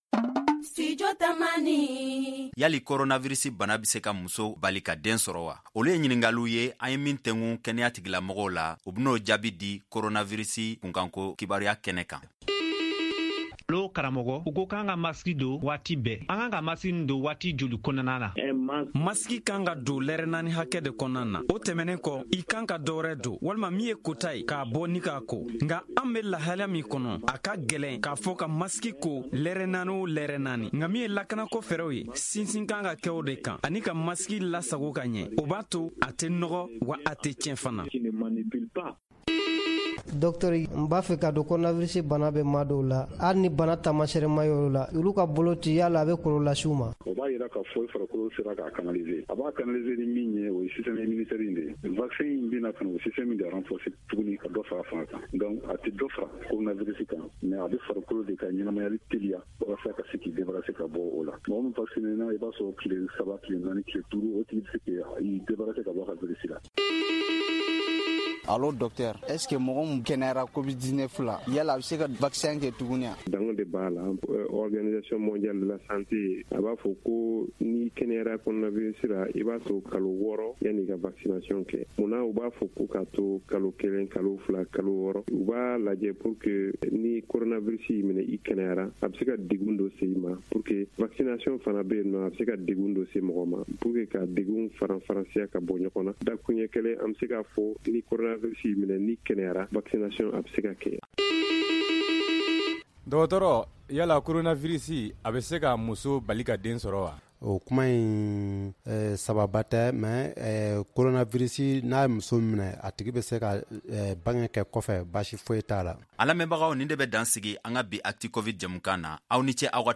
Un malade guéri du coronavirus doit-il faire la vaccination ? Est-ce que la covid-19 peut empêcher une femme d’avoir un enfant ? Ce sont entre autres questions auxquelles des spécialistes de la santé répondent dans ce numéro d‘Actu’covid.